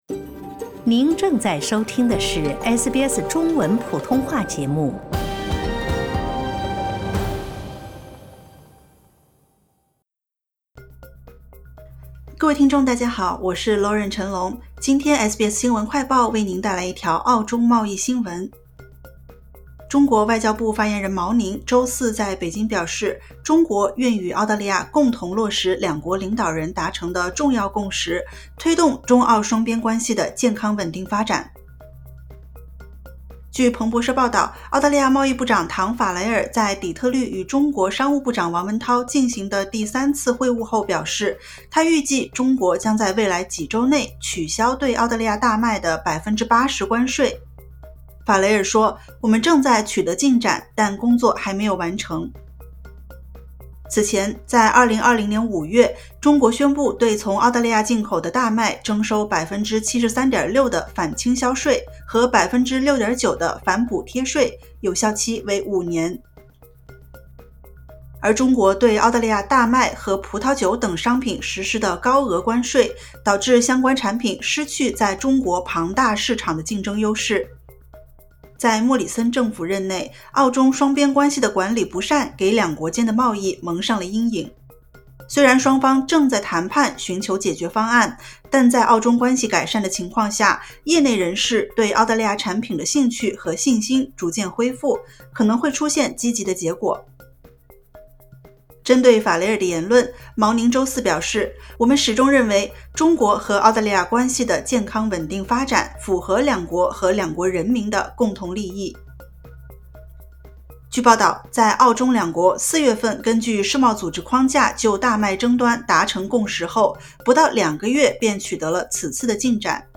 【SBS新闻快报】中方回应：中澳取消澳大麦关税谈判态势积极